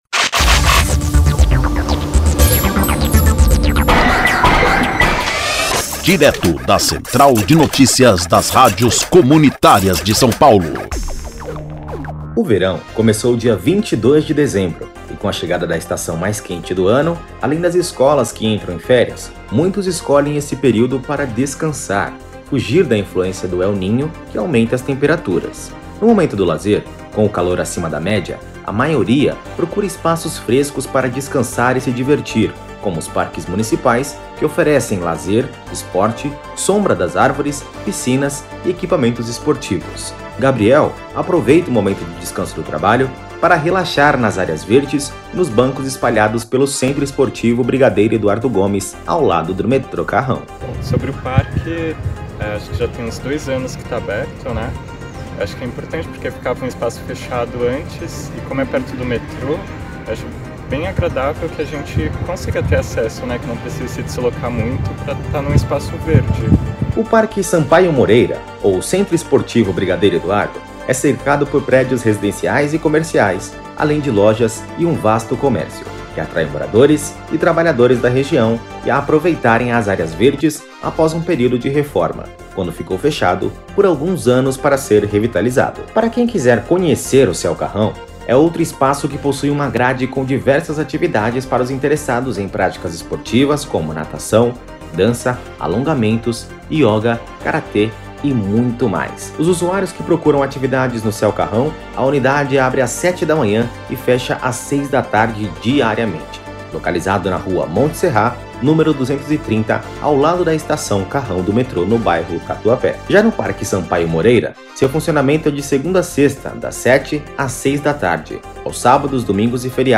INFORMATIVO: